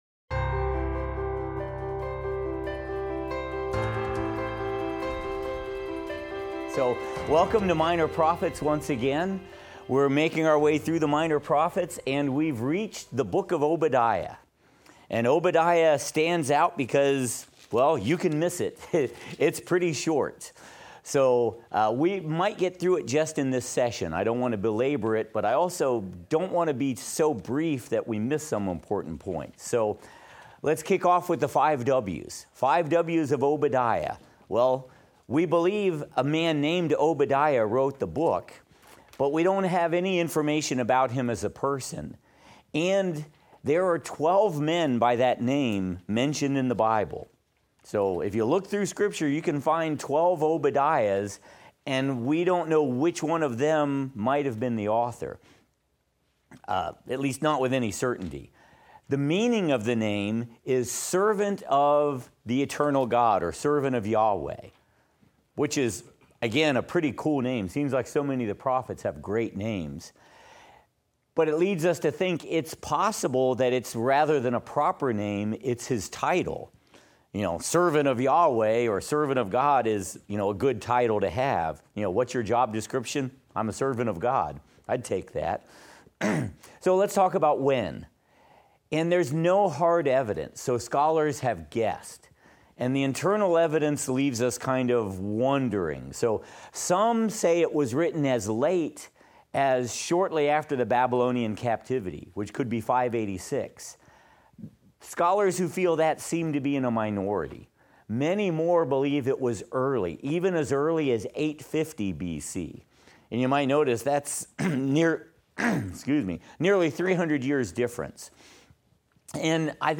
Minor Prophets - Lecture 12 - audio.mp3